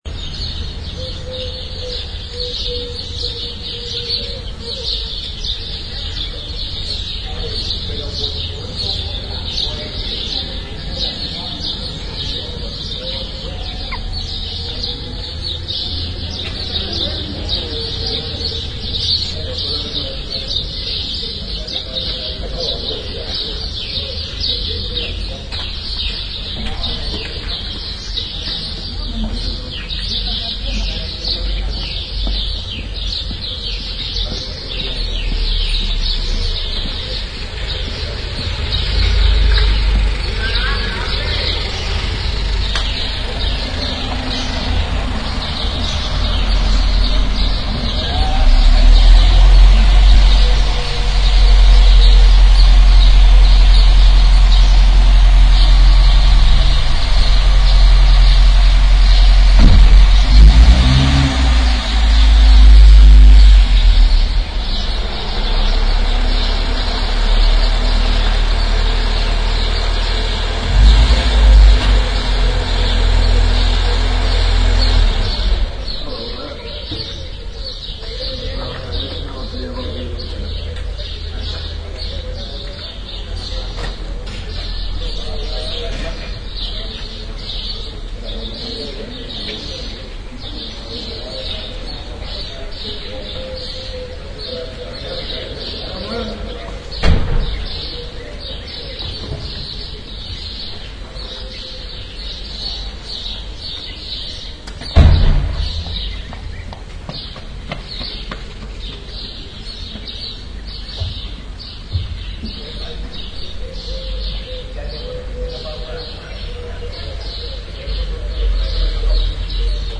Field Recordings from Spain, Gibraltar and Morocco
The „Estrecho De Gibraltar“-recordings are all left in their original form.
Recorded in Spain, Gibraltar, Morocco / 2006